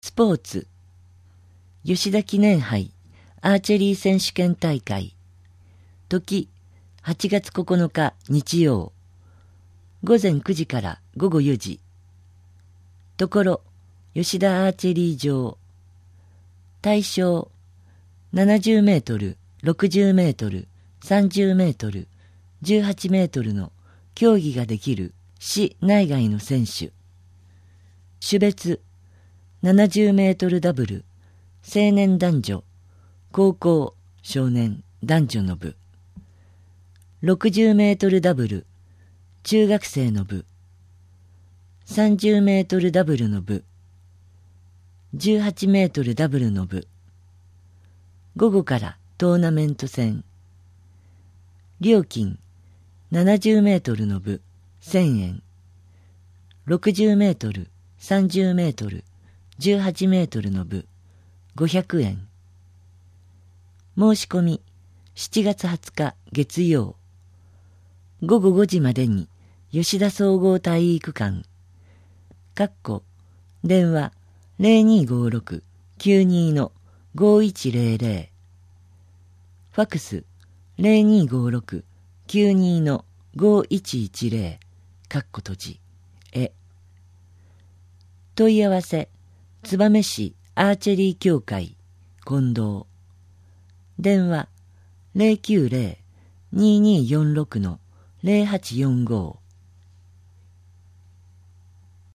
こちらではMP3版の声の広報を、項目ごとに分けて配信しています。